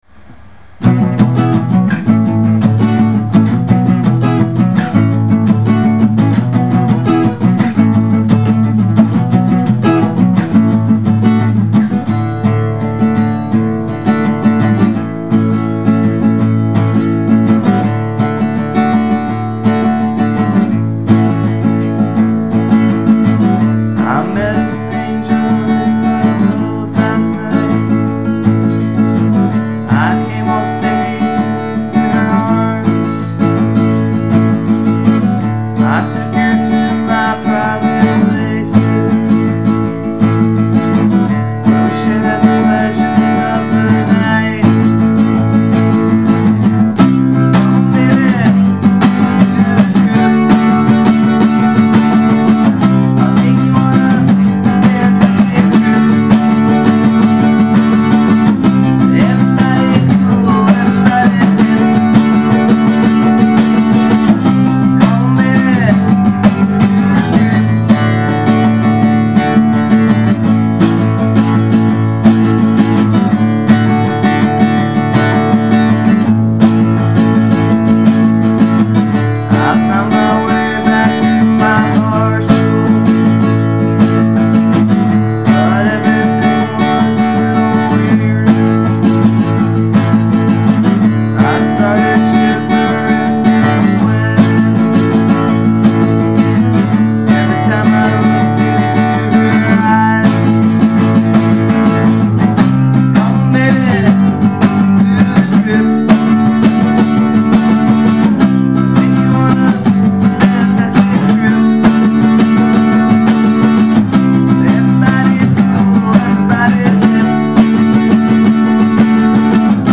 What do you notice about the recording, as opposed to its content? Unplugged